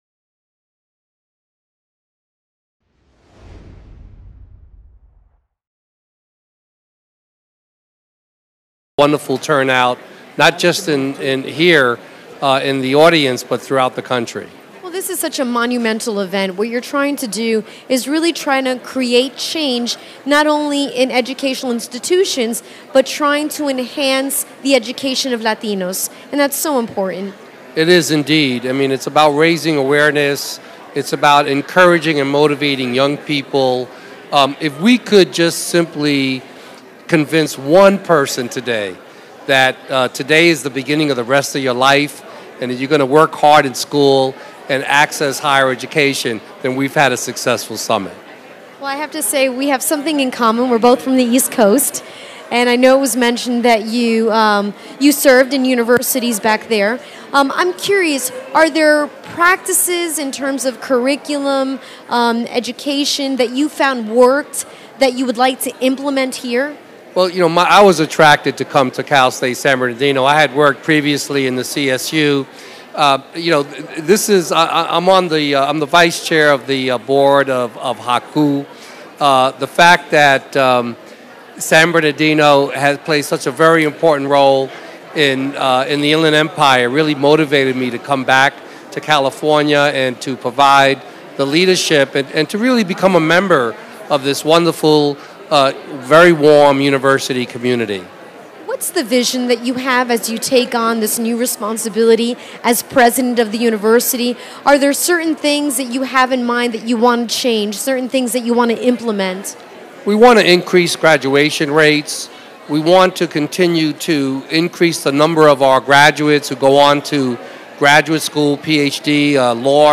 Red Carpet Interviews